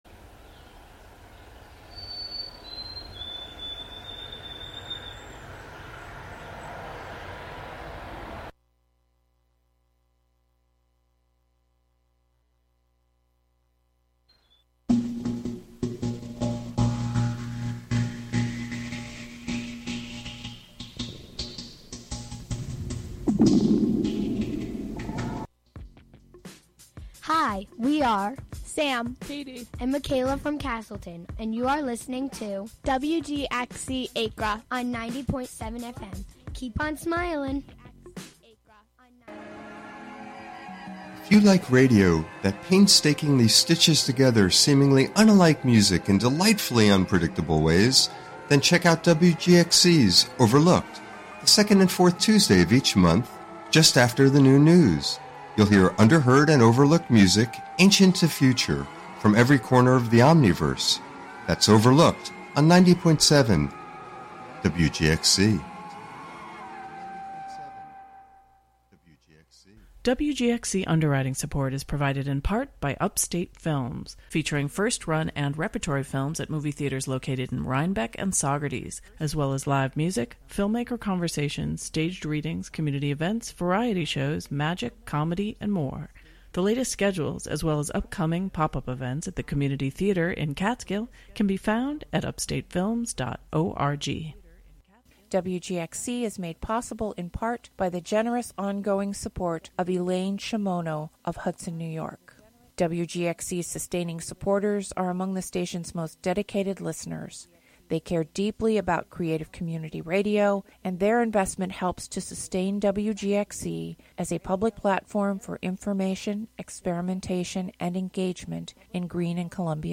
Counting down ten new sounds, stories, or songs, "American Top 40"-style. Usually the top ten is recent songs, but sometimes there are thematic countdowns, or local music-themed shows.